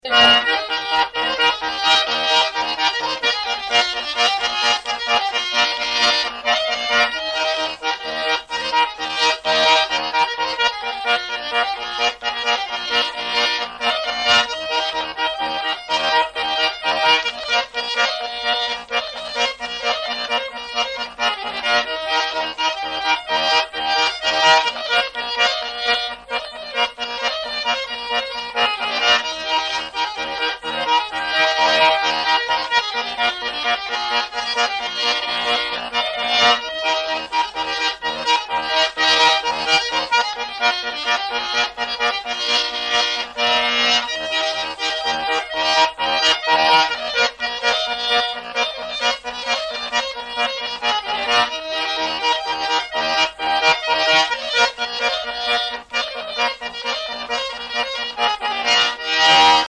Polka